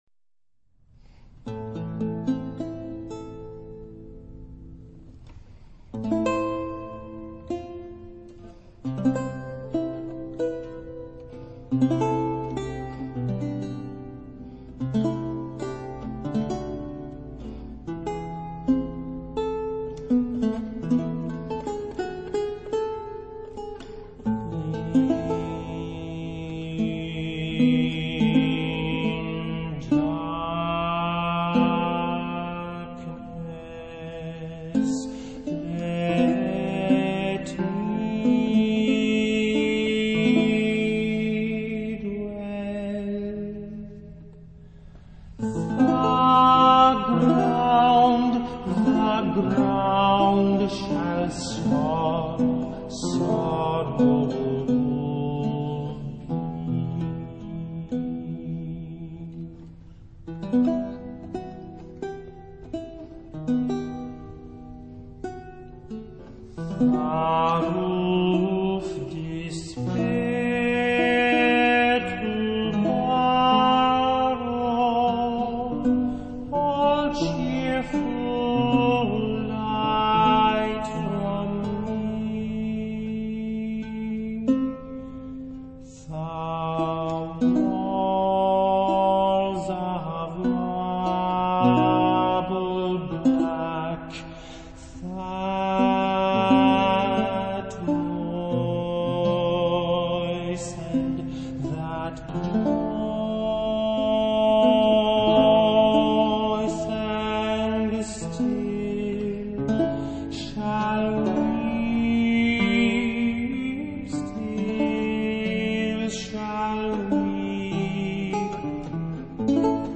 classical
chamber music
vihuela, renaissance and baroque lute